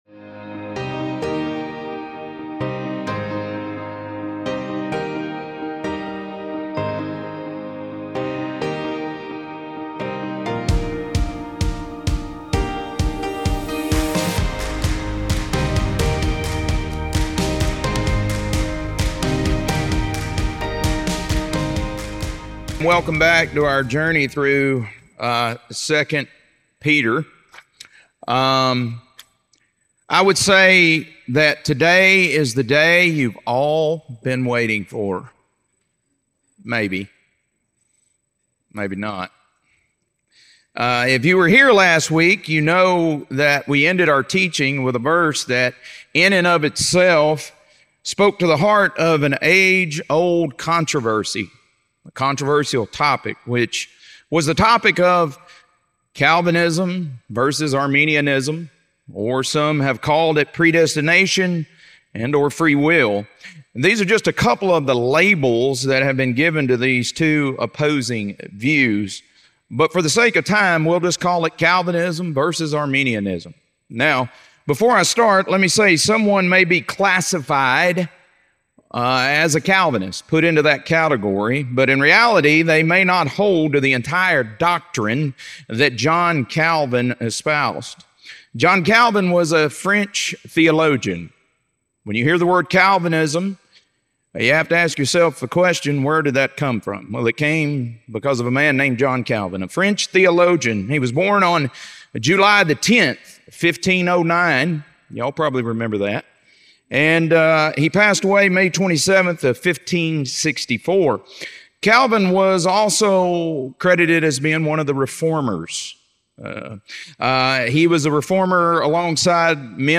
2 Peter - Lesson 3B | Verse By Verse Ministry International